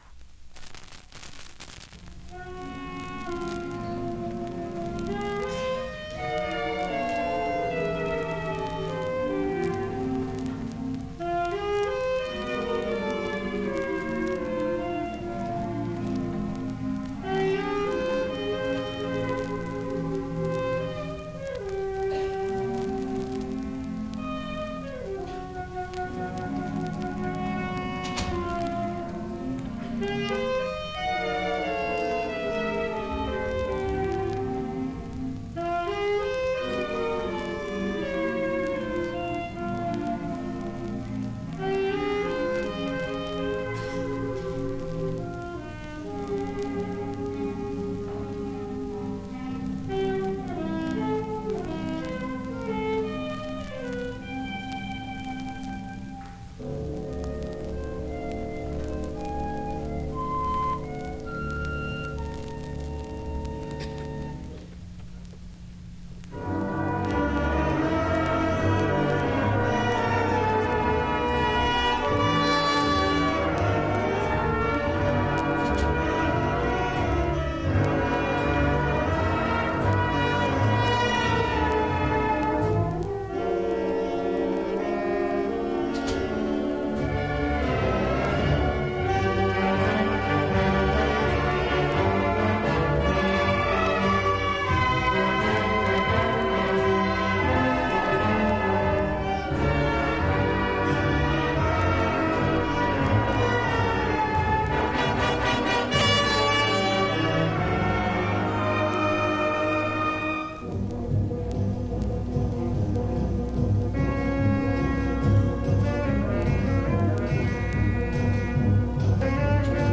“An Ellington Portrait” from HCI Music Night 1972 by Senior Band.